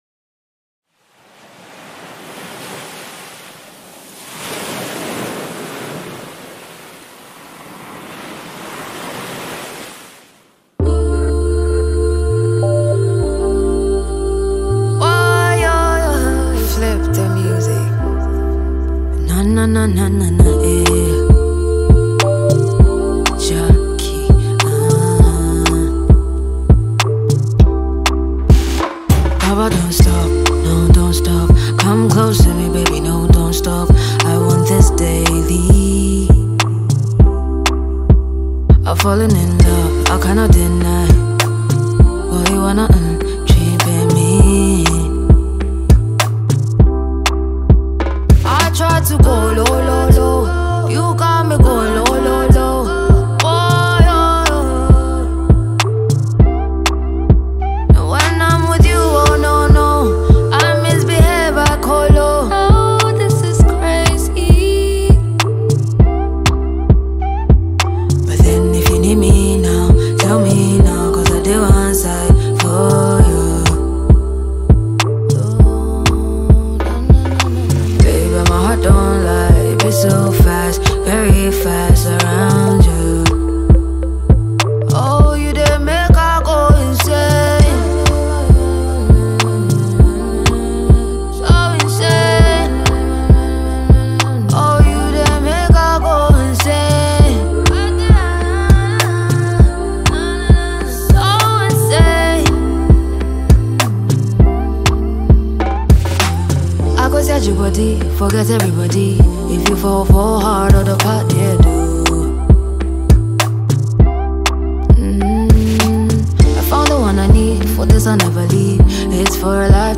Sensational lyrical blessed Ghanaian singer